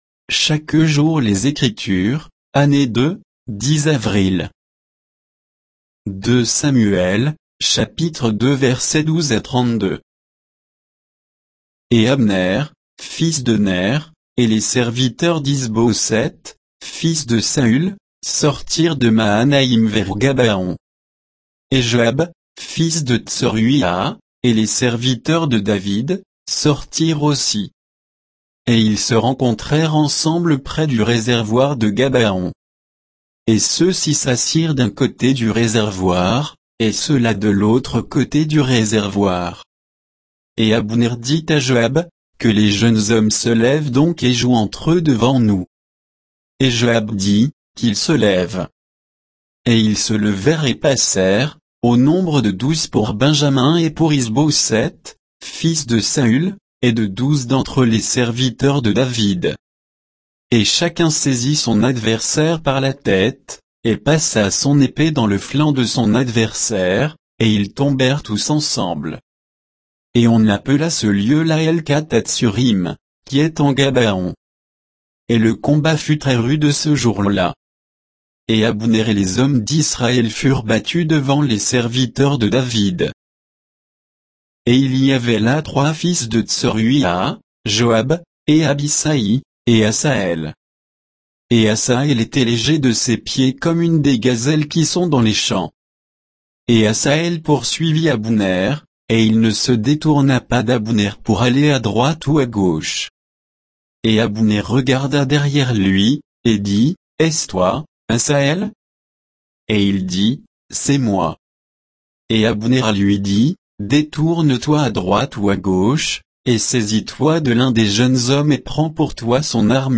Méditation quoditienne de Chaque jour les Écritures sur 2 Samuel 2, 12 à 32